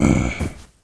spawners_mobs_mummy_death.ogg